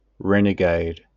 Ääntäminen
UK : IPA : /ˈɹɛ.nəˌɡeɪd/ US : IPA : /ˈɹɛ.nɪˌɡeɪd/